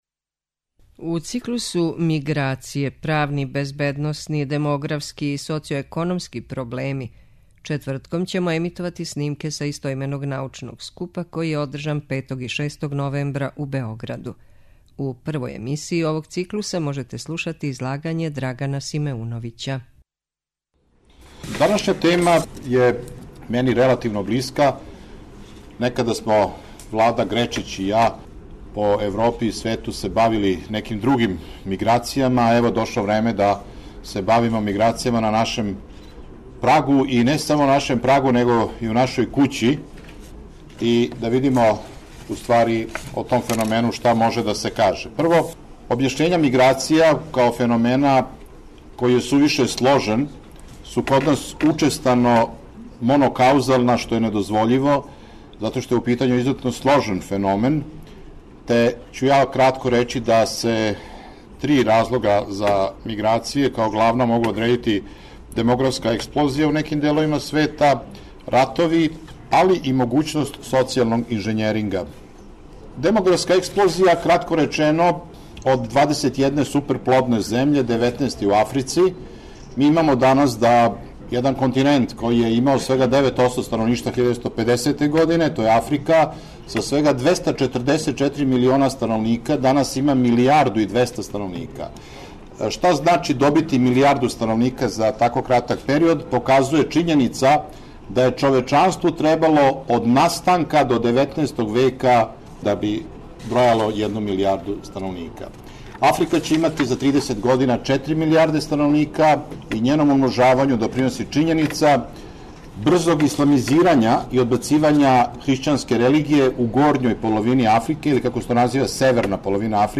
У циклусу МИГРАЦИЈЕ: ПРАВНИ, БЕЗБЕДНОСНИ, ДЕМОГРАФСКИ И СОЦИО-ЕКОНОМСКИ ПРОБЛЕМИ четвртком ћемо емитовати снимке са истоименог научног скупа који је одржан 5. и 6. новембра у Хотелу Палас у Београду.
Научни скупови